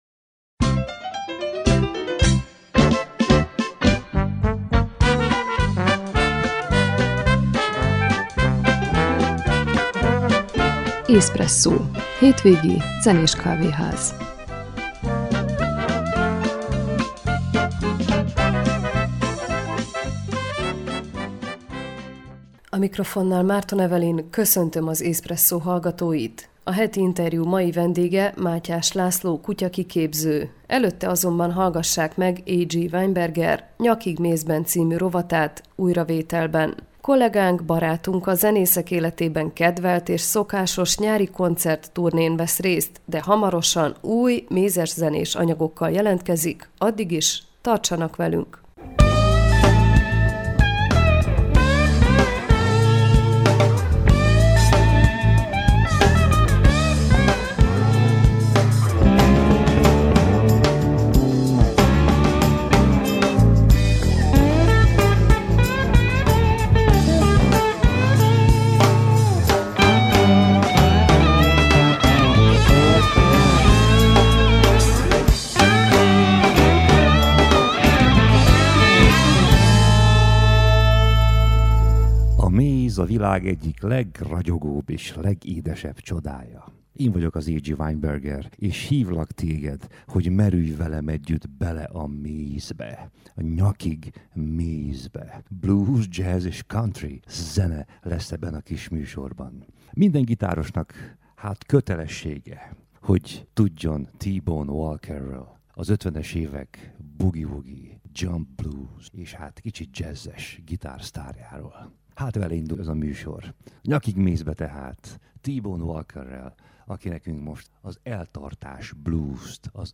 A Heti interjú mai vendége